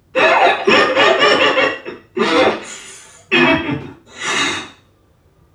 NPC_Creatures_Vocalisations_Robothead [53].wav